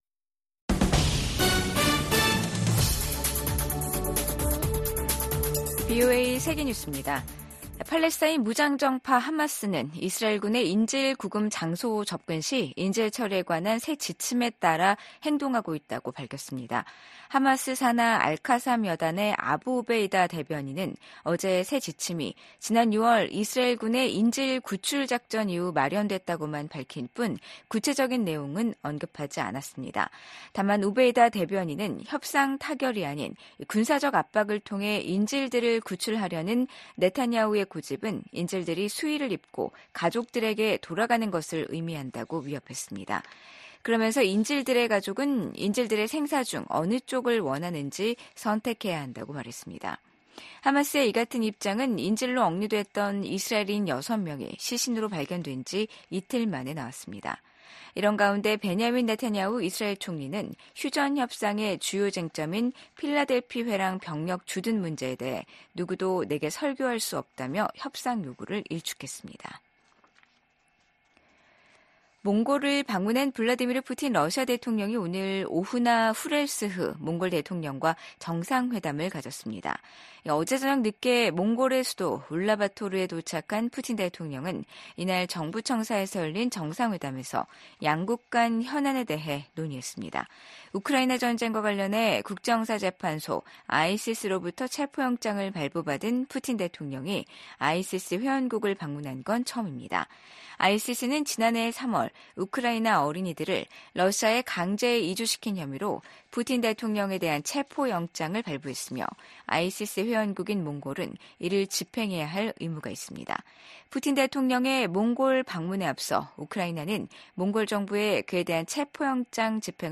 VOA 한국어 간판 뉴스 프로그램 '뉴스 투데이', 2024년 9월 3일 3부 방송입니다. 신종코로나바이러스 사태 이후 최대 규모의 백신 접종 캠페인이 북한 전역에서 시작됐습니다. 유엔 사무총장이 북한의 열악한 인권 상황을 거듭 우려하면서 인권 유린 가해자들을 처벌하라고 촉구했습니다. 중국 정부가 중국 여자 프로농구에 진출한 북한 선수의 갑작스러운 귀국 보도와 관련해 이례적으로 ‘대북제재’를 언급했습니다.